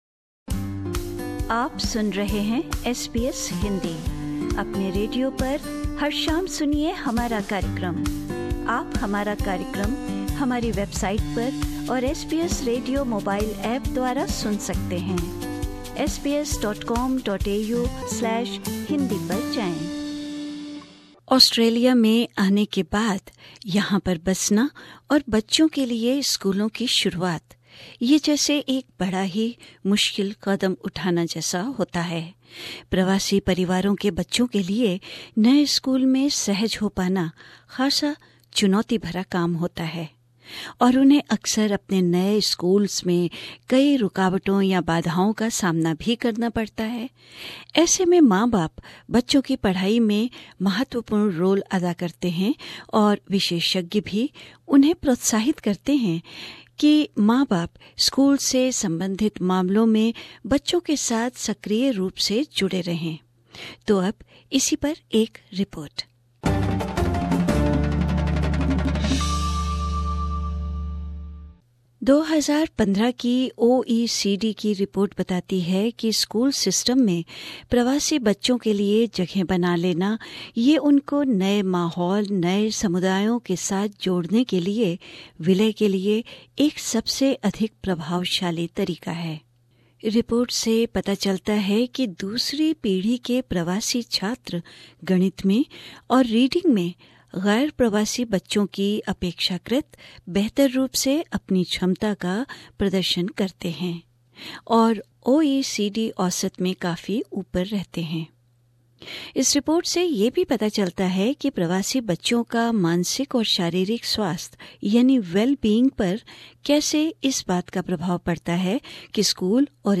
एक रिपोर्ट